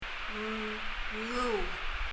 m のあとの ng がうまく出せない私にたまりかねて、ゆっくりお手本を聴かせてくれました
発音
これは、うまくできない私を見かねて、わざとわかりやすく発音してくれたもので、